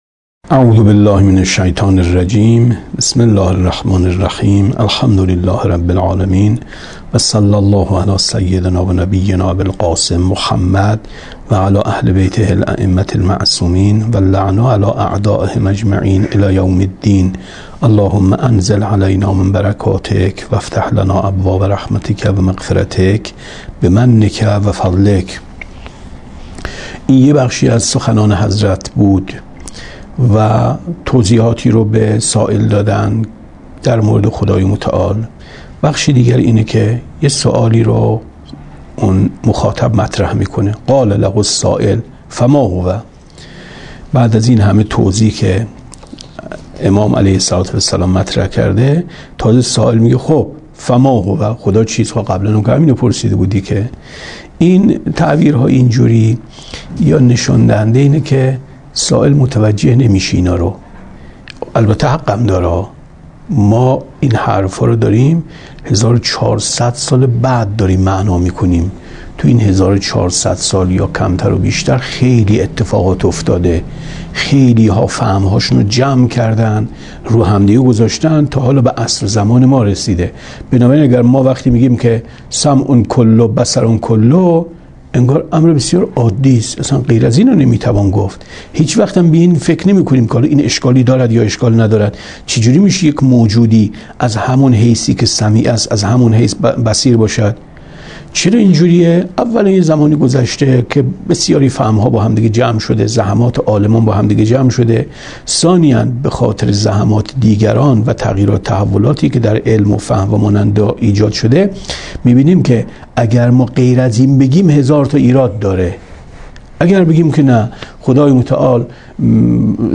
کتاب توحید ـ درس 22 ـ 16/ 7/ 95